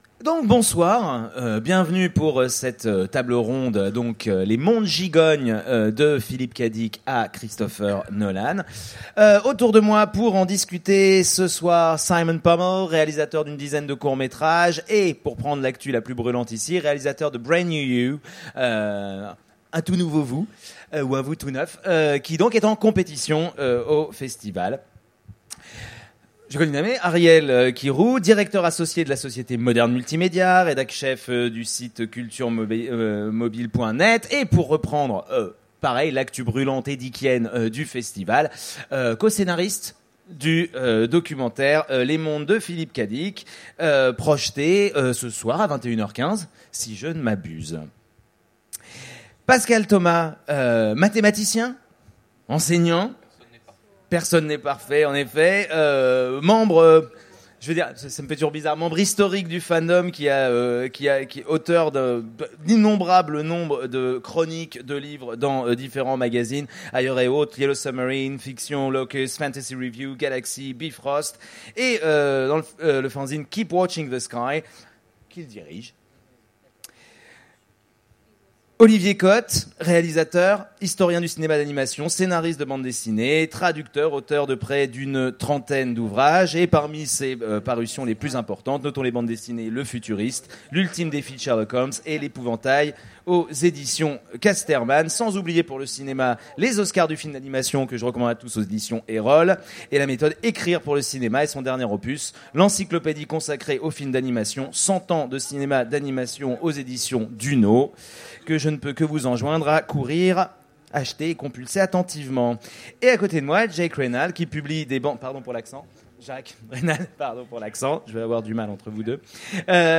Utopiales 2015 : Conférence Les réalités-gigognes, de Philip K. Dick à Christopher Nolan !